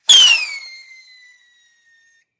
rocketfly.ogg